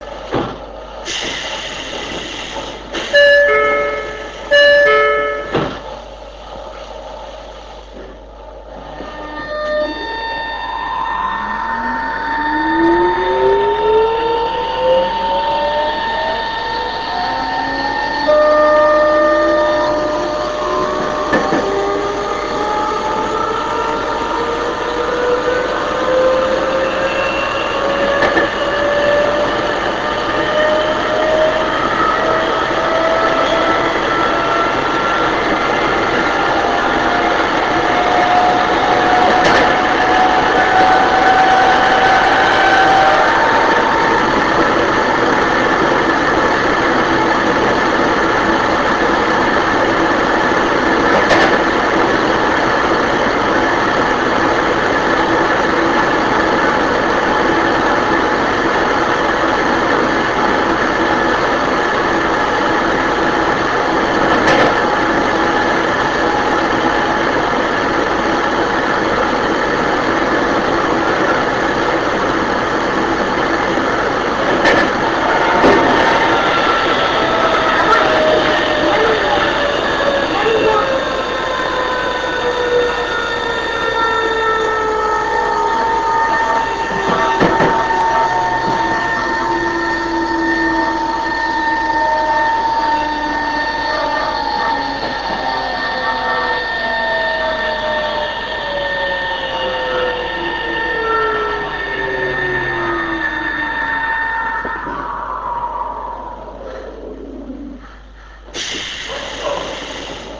０５系初〜中期車走行音 行徳→南行徳 65.1Kb RealAudio形式
音階チョッパの音がよく聞こえてきます。